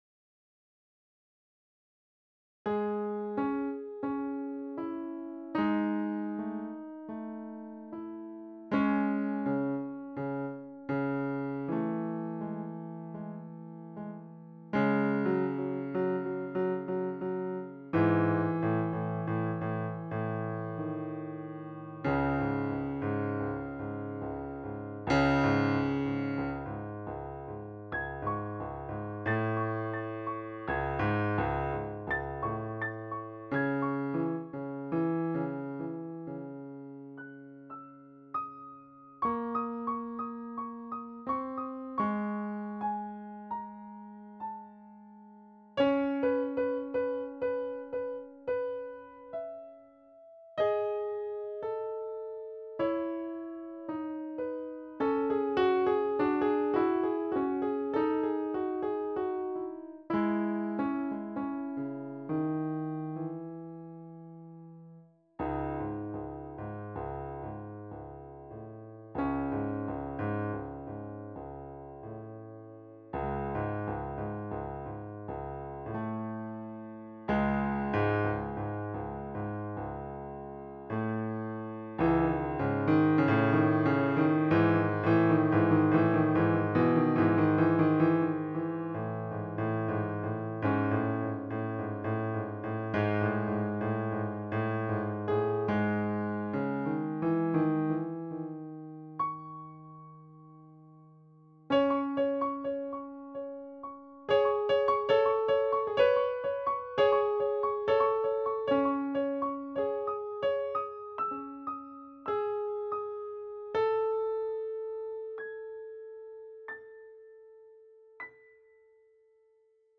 Posted in Classical, Piano Pieces Comments Off on